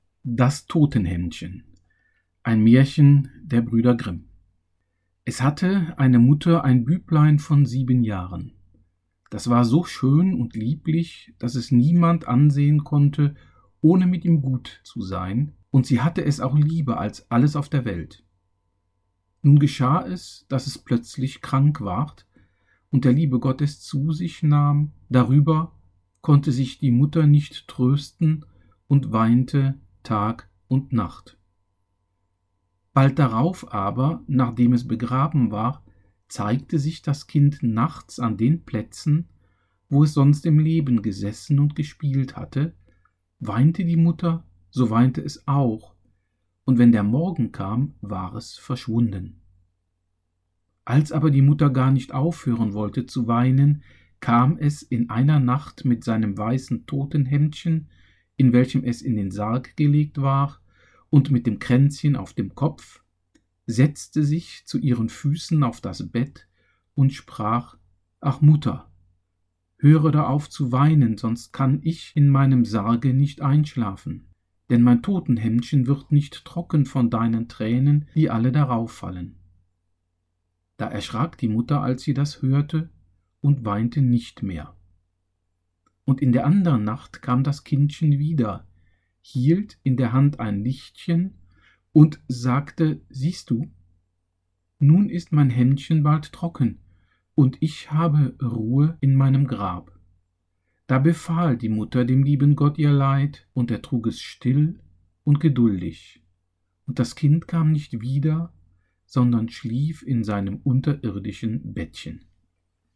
Mein Totenhemdchen – ein Hörangebot
Man muss kein geschulter Hörer sein, um festzustellen, dass der Sprecher keine prononcierte Radiostimme hat, dass seine Stimme nicht geschult ist und die Aufnahme nicht gerade professionell gemacht wurde.